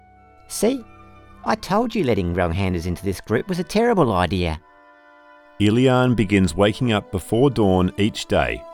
Male
English (Australian)
Deep, comforting, trustworthy, steady voice.
Character / Cartoon
Character Voice Narrative
Words that describe my voice are Trustworthy, Calm, Deep.
1228Sample_CharacterVoiceExample.mp3